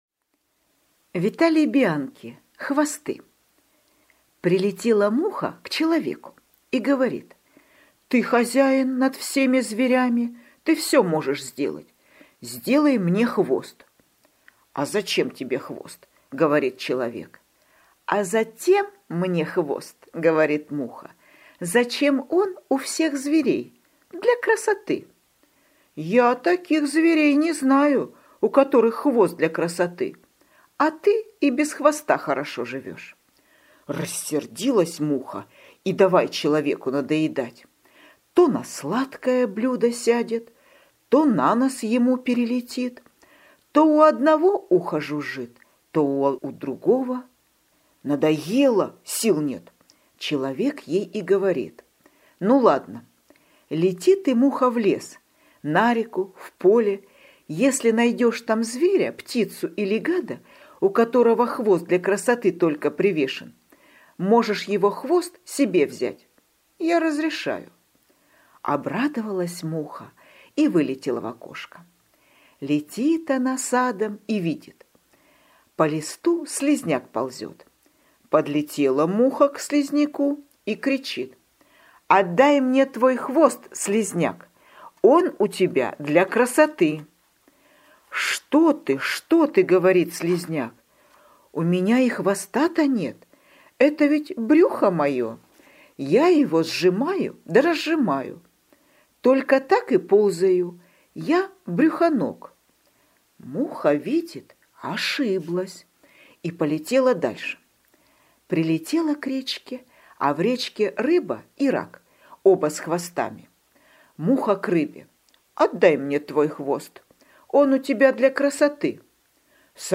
Хвосты – Бианки В.В. (аудиоверсия)
Аудиокнига в разделах